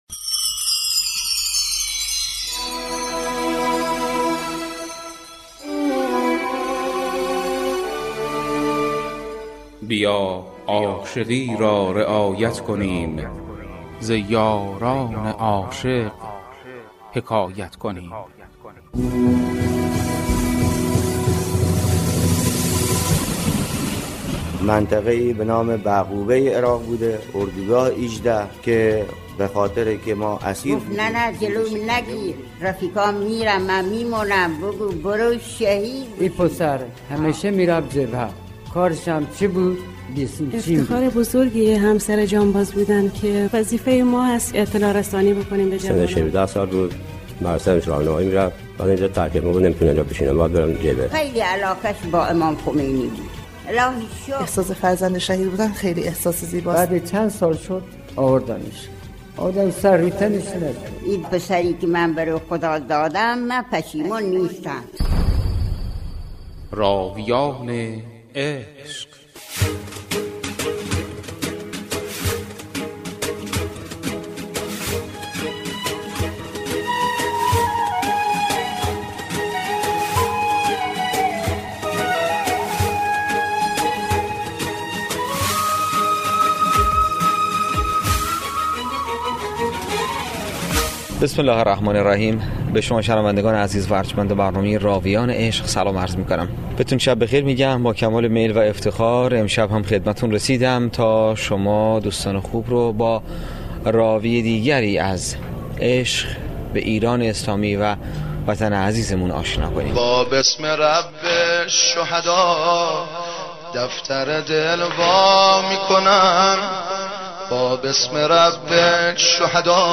گفتگوی رادیویی
این برنامه با مشارکت معاونت فرهنگی بنیاد شهید و امور ایثارگران استان هرمزگان و گروه بسیج صدای مرکز خلیج فارس تهیه و از شبکه رادیویی این استان پخش گردیده است .
مصاحبه رادیویی